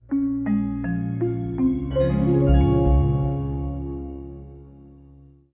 startup.wav